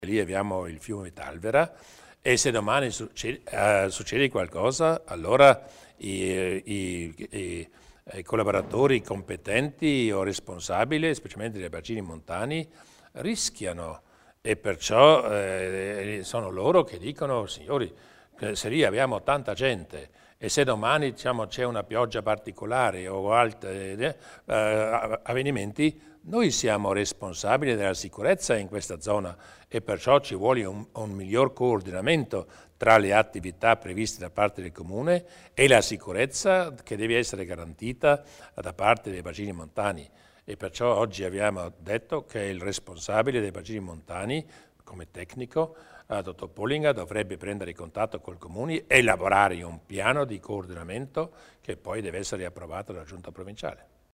Il Presidente Durnwalder spiega le iniziative per garantire la sicurezza sui prati del Talvera